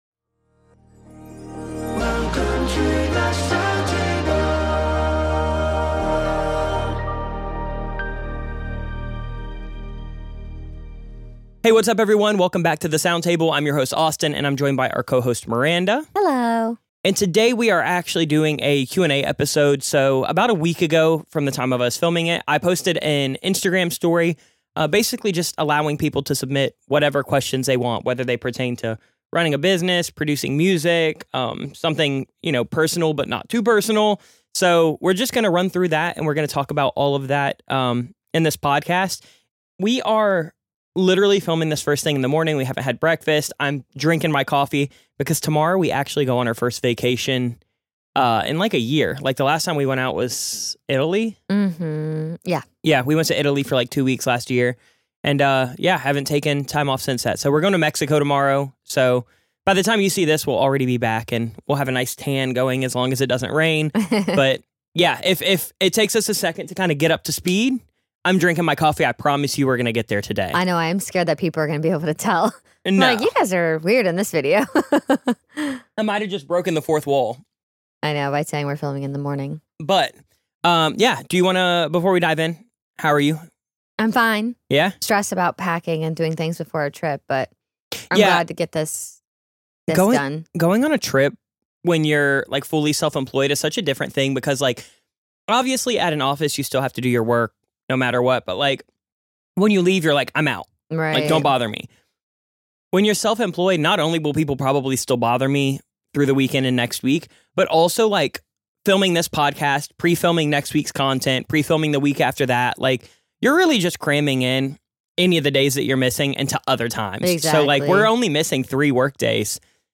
Kick back, relax, and enjoy captivating conversations that will leave you inspired and entertained.